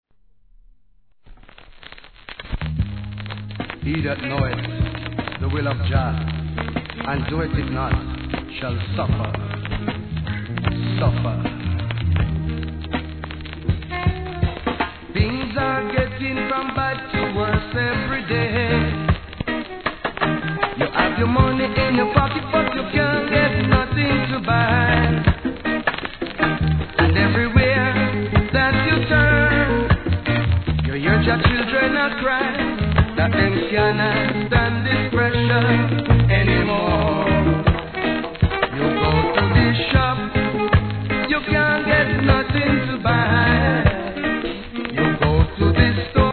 序盤にチリ入りますが徐々に落ち着きます
REGGAE